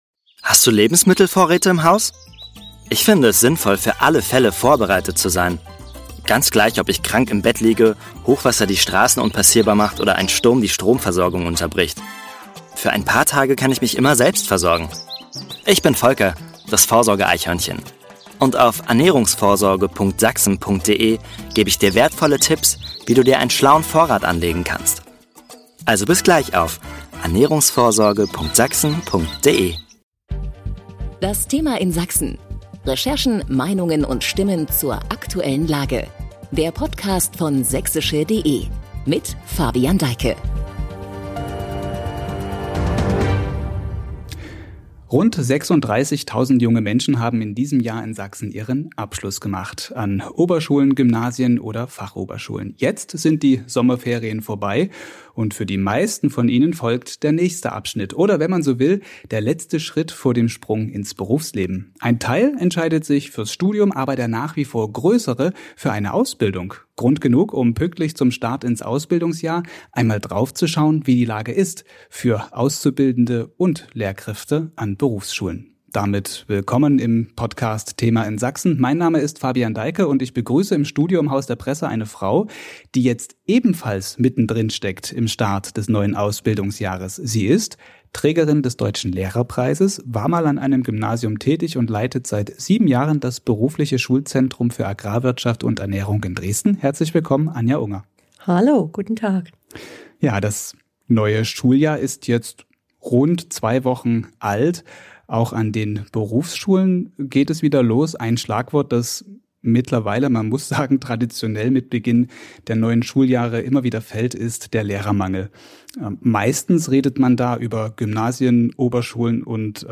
so klingt es in dem Podcast-Interview immer wieder durch